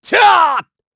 Clause_ice-Vox_Attack3_kr.wav